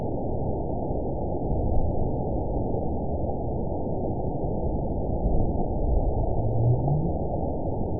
event 922873 date 04/30/25 time 00:55:49 GMT (7 months ago) score 9.57 location TSS-AB02 detected by nrw target species NRW annotations +NRW Spectrogram: Frequency (kHz) vs. Time (s) audio not available .wav